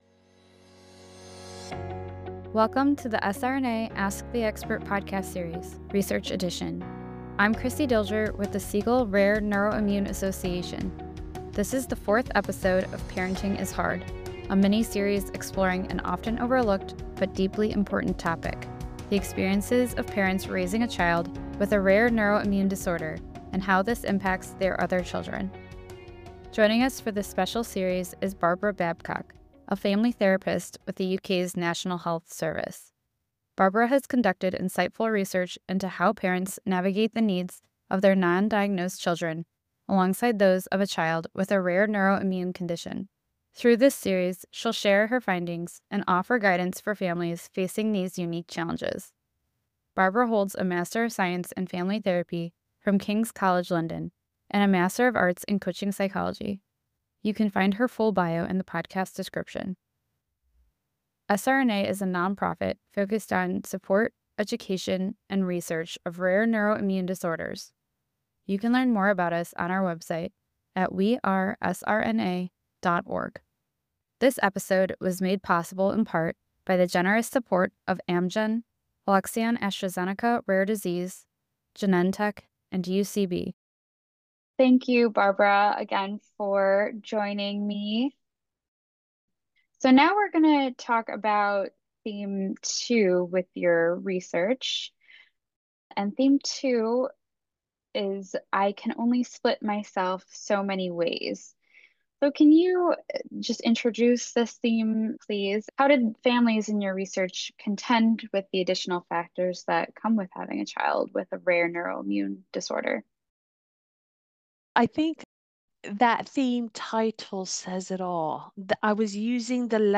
Q&A episode